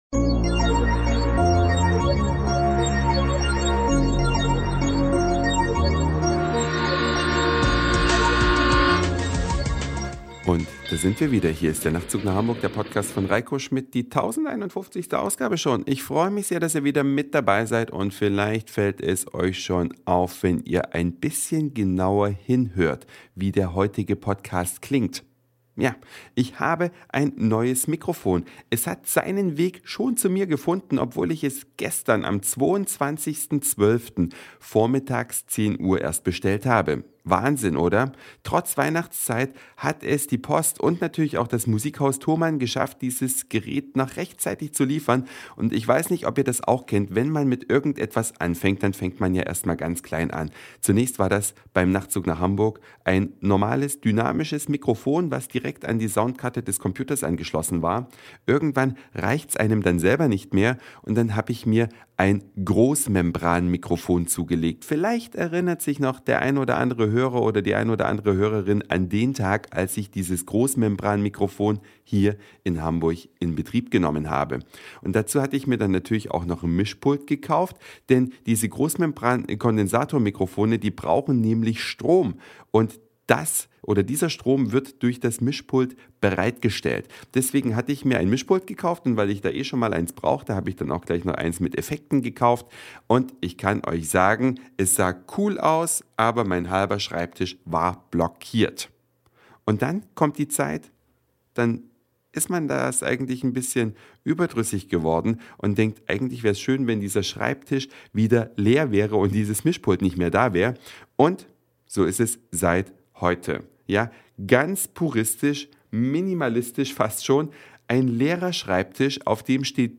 Das RØDE Podcaster Mikrofon ist ab heute bei mir im Einsatz.